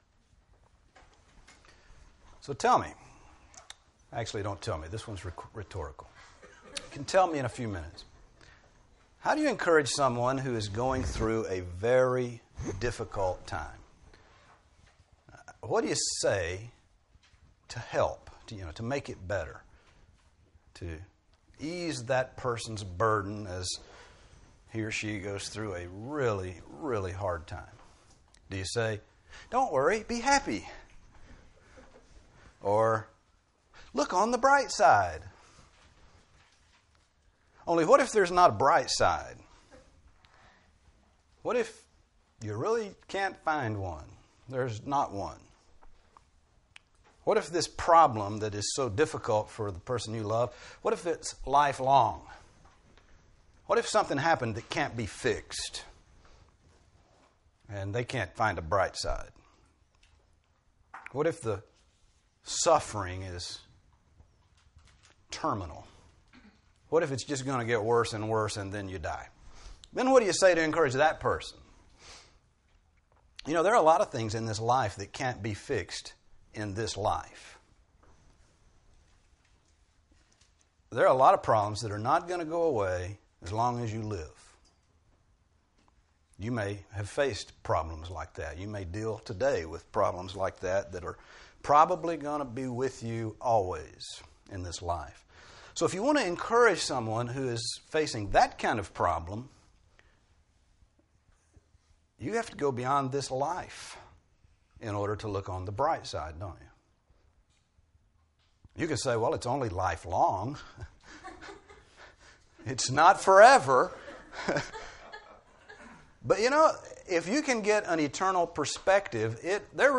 Grace Bible Church - Sermons